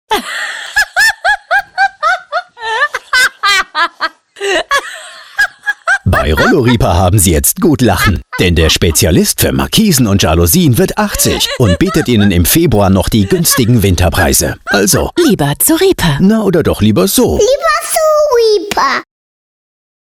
Während der eine Spot Informationen über die Winterpreise, das 80-jährige Bestehen des Unternehmens und den neuen Standort in der Nähe vom Weserpark mit einem sehr sympathischen und ansteckenden Lachen kombiniert, erzählt der andere Spot eine Geschichte zweier Frauen, die ganz gespannt ihre Nachbarn beobachten.